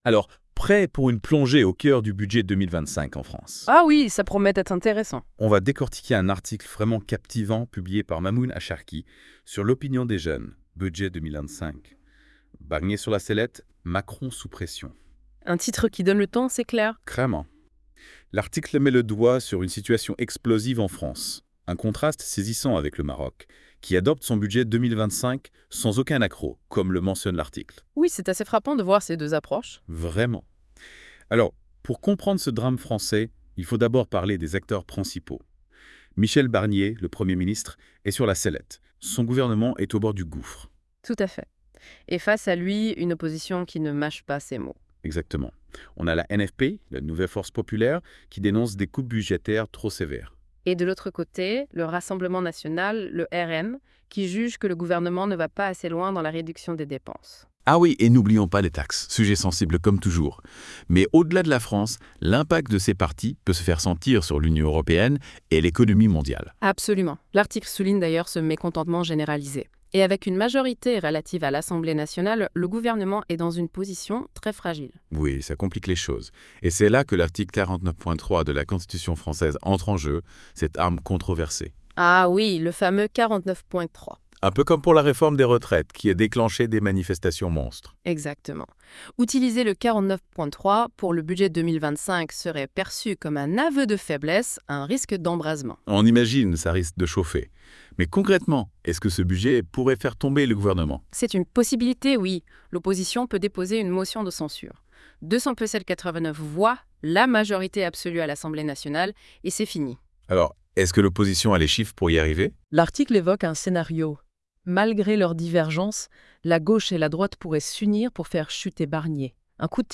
Débat à ècouter (22.69 Mo)